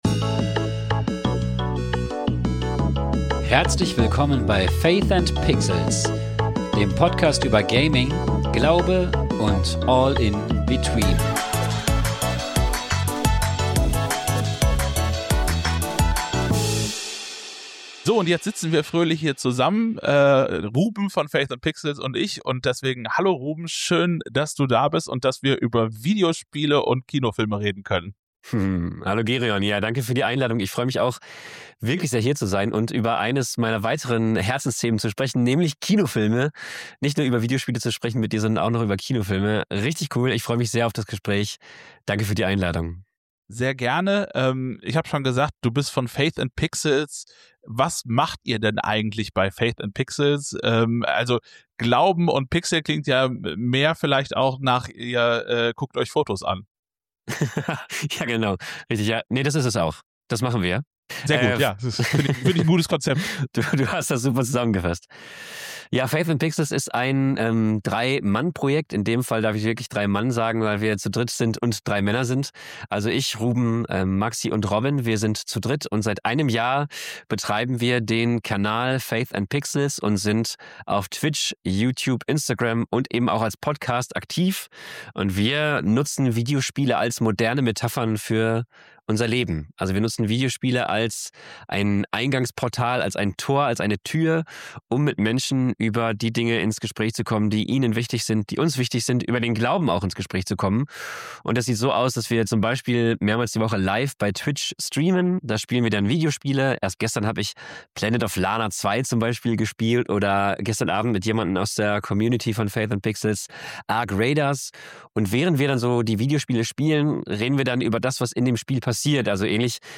Und warum sind manche Adaptionen einfach näher am Original als andere? Ein Gespräch über Nostalgie, Erwartungen und die Frage, was gute Videospielverfilmungen eigentlich ausmacht.